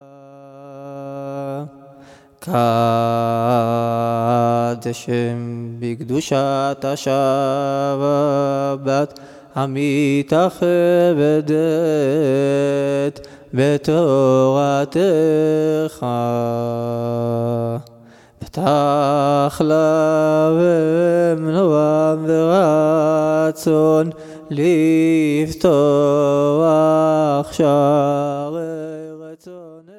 Folk